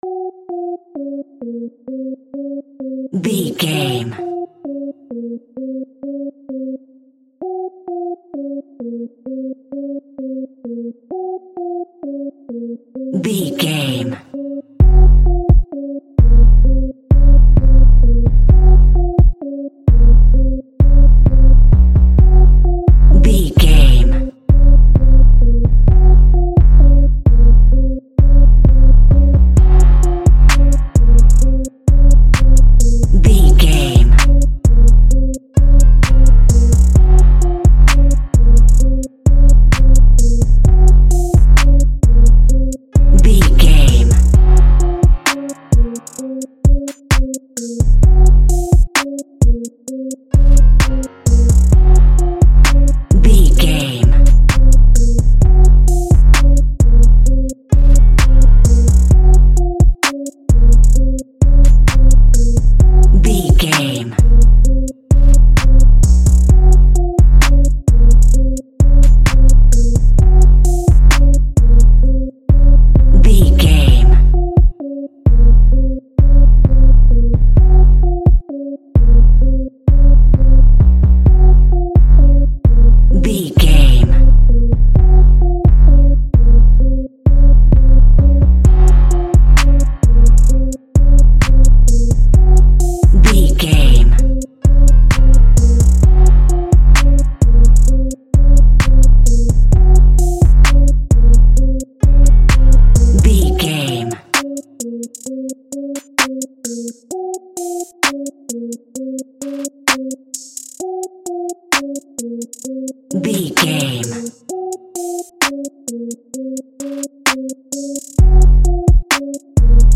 Ionian/Major
aggressive
intense
driving
bouncy
energetic
dark
drums